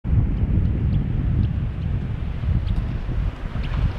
Chorlito Doble Collar (Anarhynchus falklandicus)
Nombre en inglés: Two-banded Plover
Fase de la vida: Adulto
Localidad o área protegida: Ea. Las Lajas
Condición: Silvestre
Certeza: Observada, Vocalización Grabada
Chorlito-de-Doble-Collar.mp3